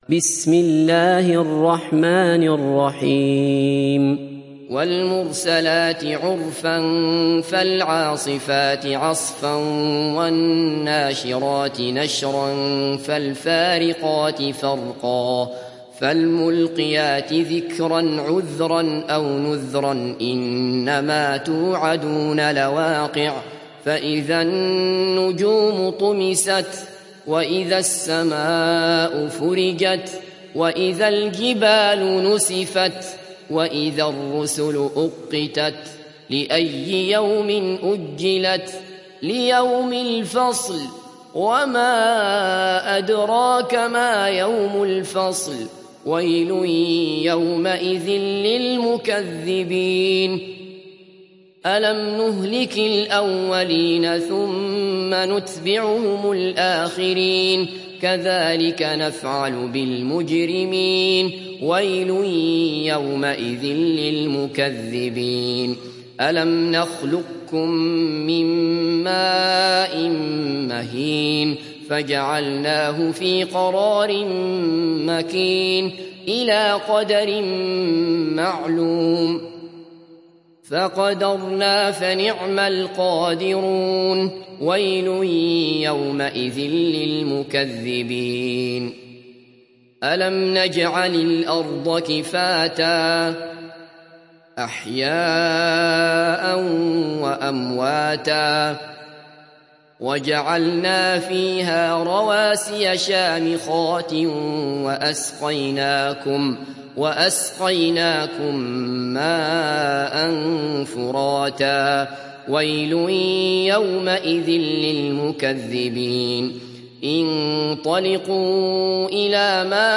تحميل سورة المرسلات mp3 بصوت عبد الله بصفر برواية حفص عن عاصم, تحميل استماع القرآن الكريم على الجوال mp3 كاملا بروابط مباشرة وسريعة